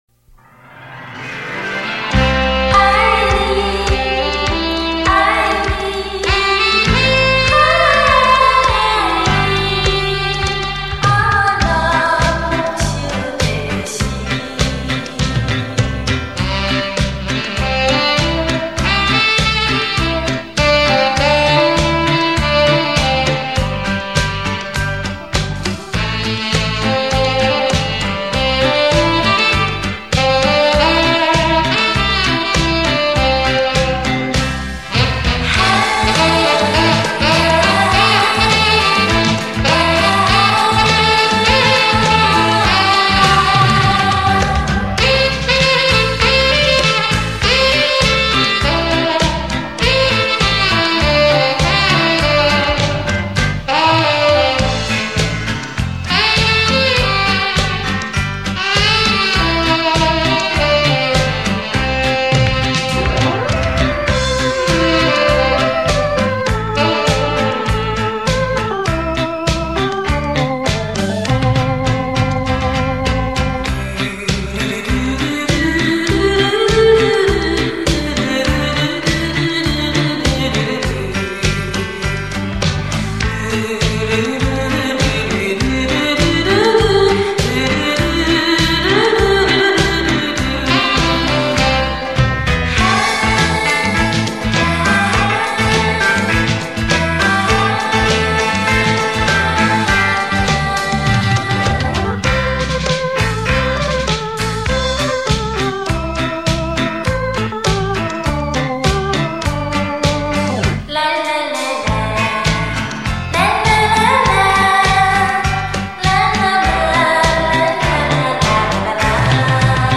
錄音：佳聲錄音室
閒適優雅的音符，完美傳真的音質，興來CD雷射唱片，呈獻在您的耳際
優美動聽的旋律讓人沉醉其中...